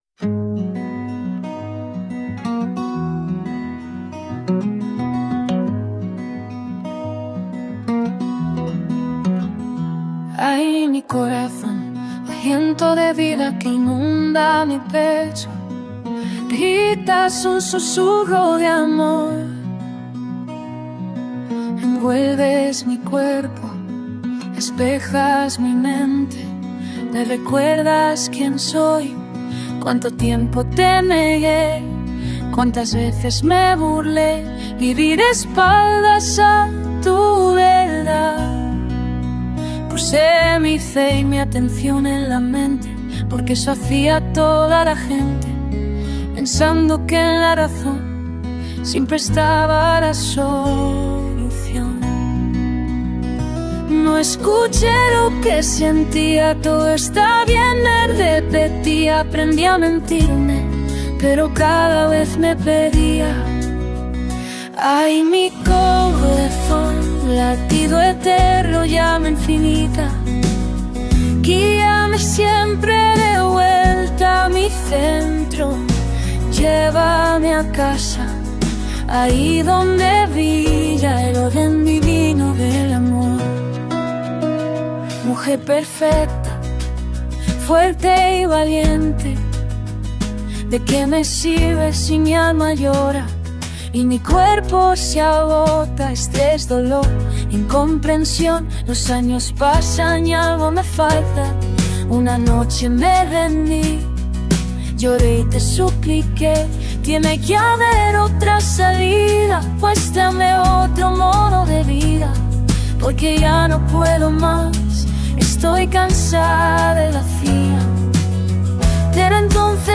Corazon-Estilo-cantautor-acustico.m4a